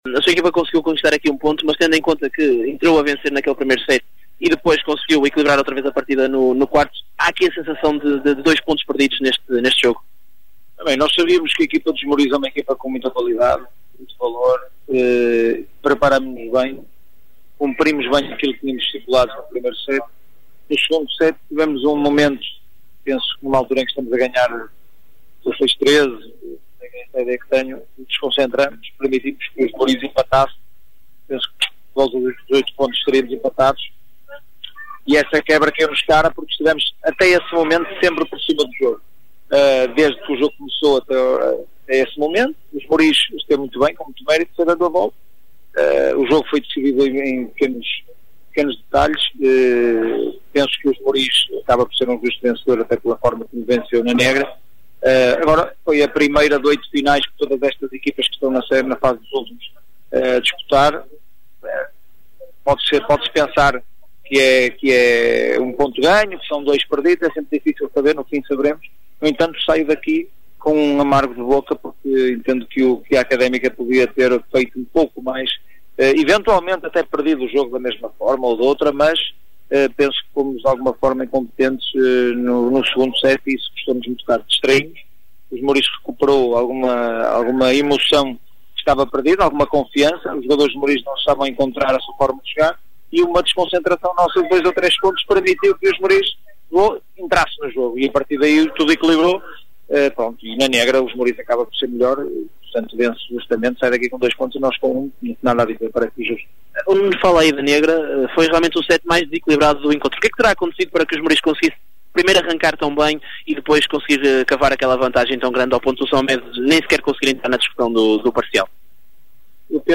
• Declarações AA S. Mamede